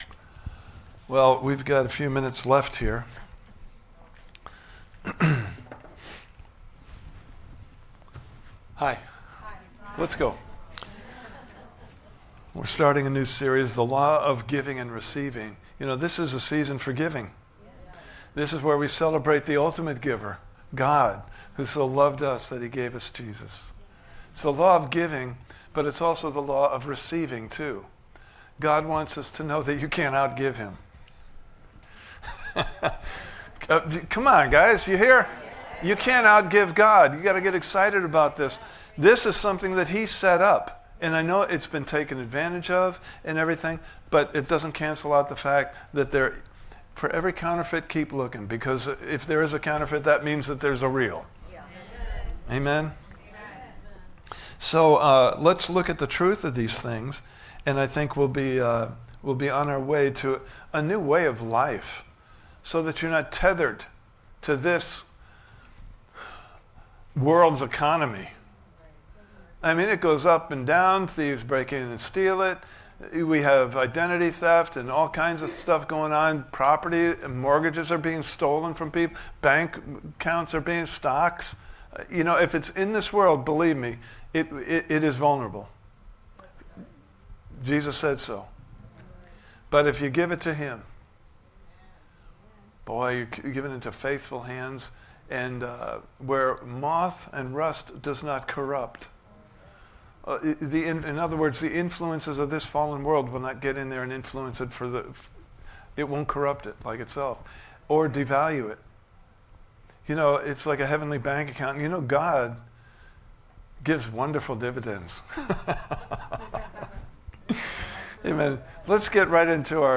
Series: The Law of Giving and Receiving Service Type: Sunday Morning Service